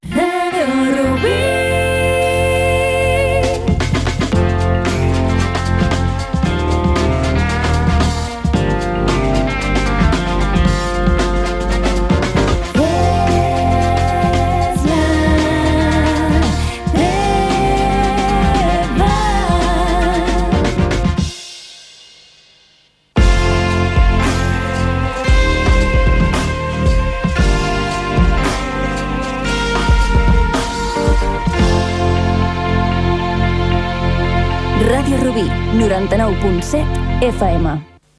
ce13ca9cd4e051ce1ba830863b2a5cc249c55124.mp3 Títol Ràdio Rubí Emissora Ràdio Rubí Titularitat Pública municipal Descripció Indicatiu i identificació de l'emissora.